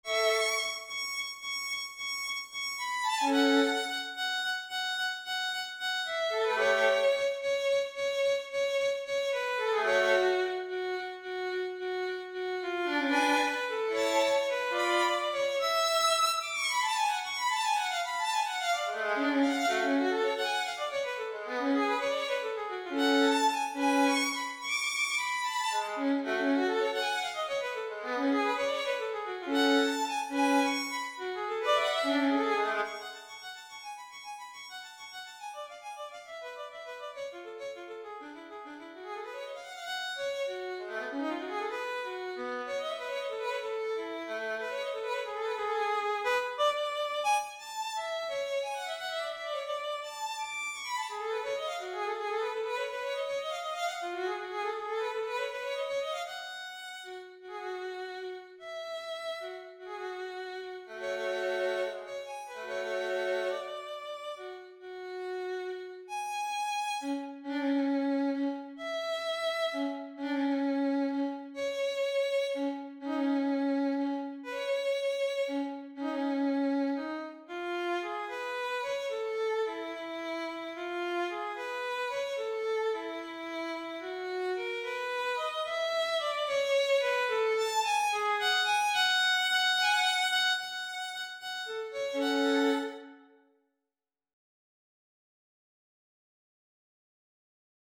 바이올린 파르티타 프렐류드 > 바이올린 | 신나요 오케스트라